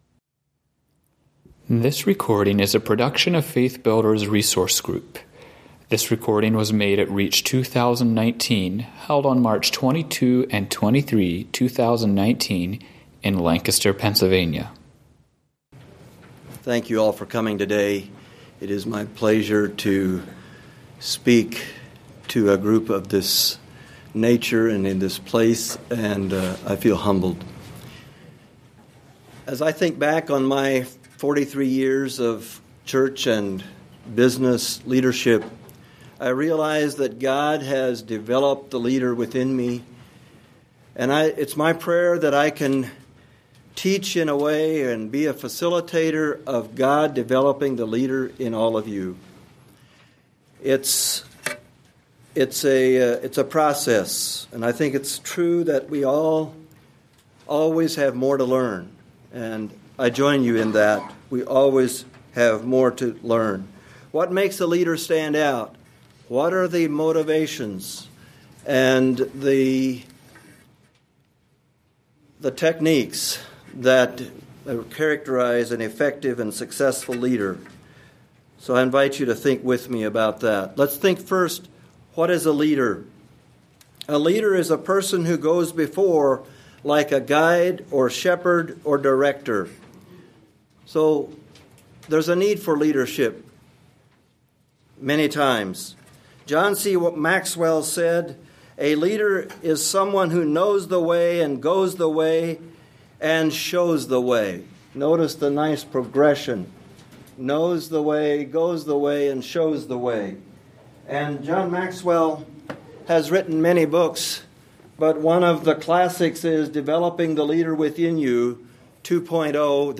Home » Lectures » Best Practices of Servant Leaders